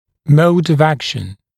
[məud əv ‘ækʃn][моуд ов ‘экшн]способ действия